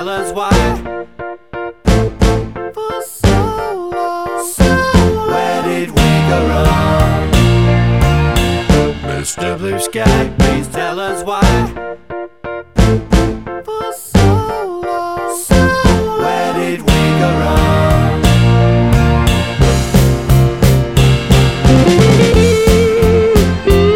No Lead Guitar Pop